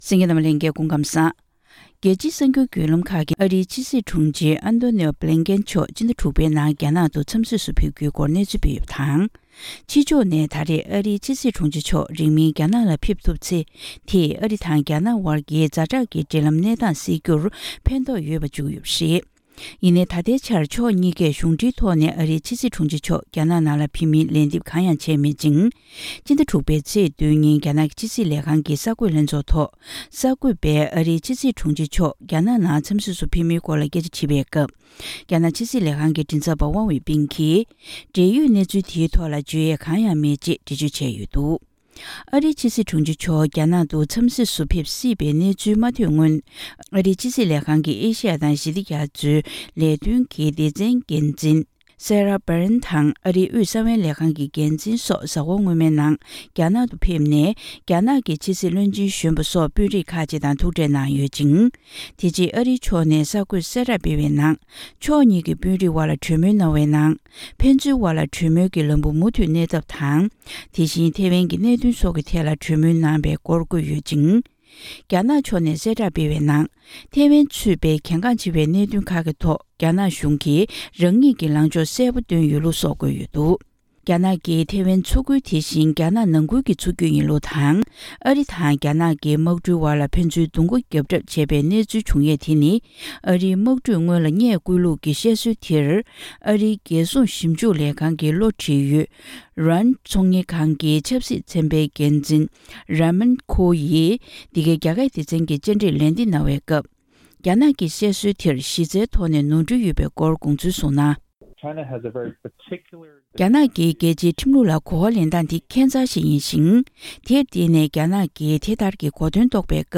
ཕྱོགས་སྒྲིག་དང་སྙན་སྒྲོན་ཞུས་པར་གསན་རོགས་ཞུ།